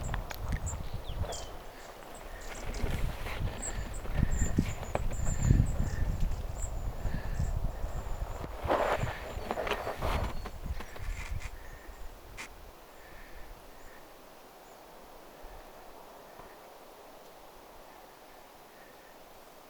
viiden pyrstötiaisen parvi lentää ihan ihan läheltä
jopa metrin päästä.
viiden_pyrstotiaisen_parvi_lentaa_aivan_lahelta_kovaa_vauhtia_rannan_suuntaisesti.mp3